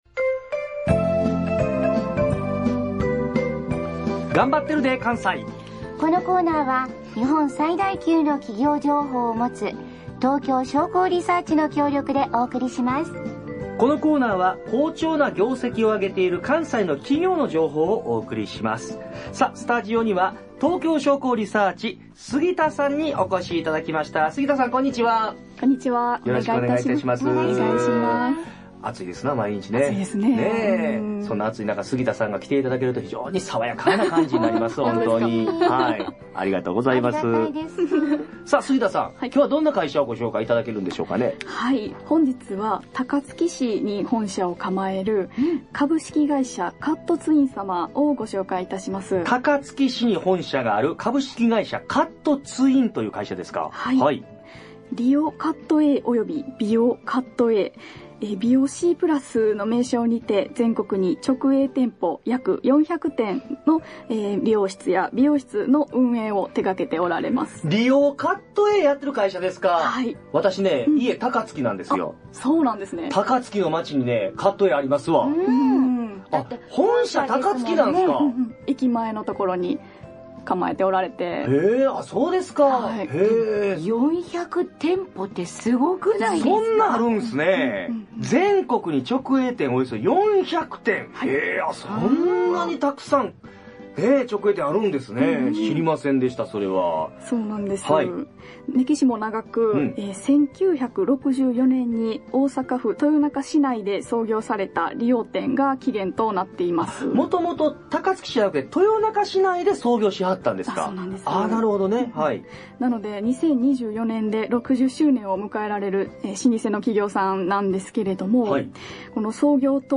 ラジオ大阪AM1314 番組名「 コッテコテの水曜日 」 コーナー名「 頑張ってるで関西 」 放送されました！